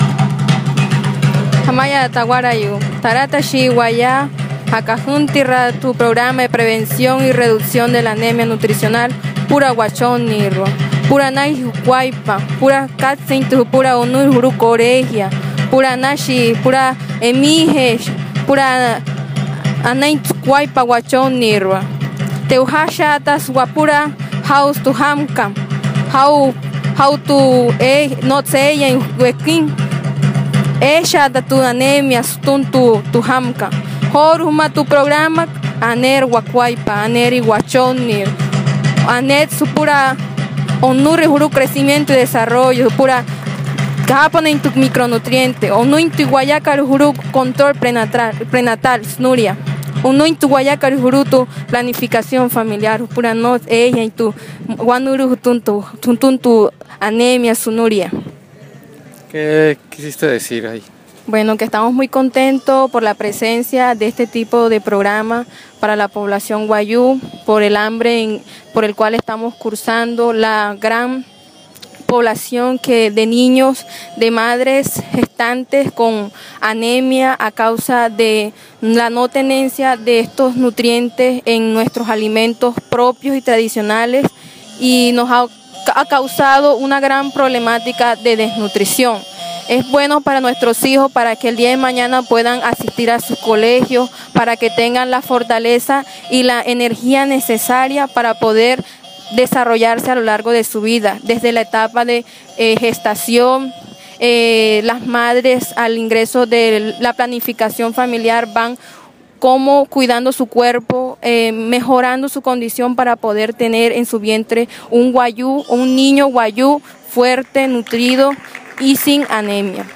Audio: Representante Wayuu en su lengua describe importancia de los micronutrientes